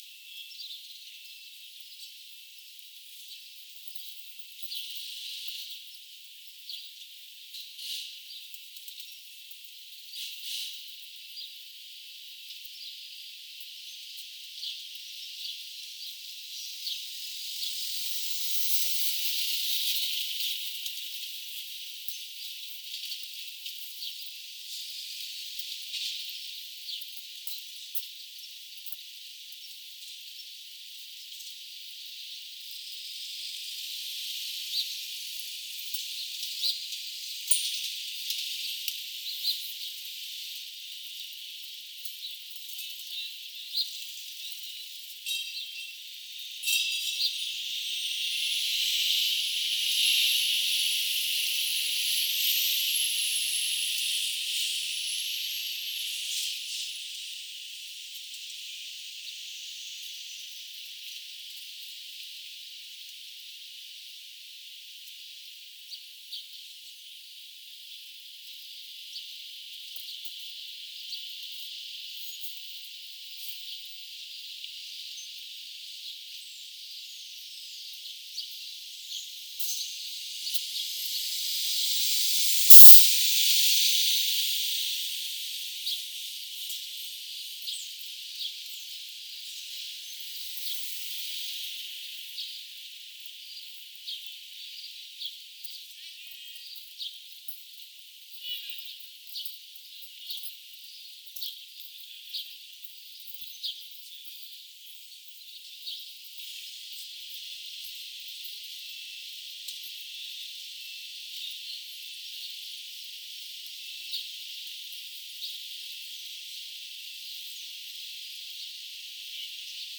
Demonstration soundscapes
anthropophony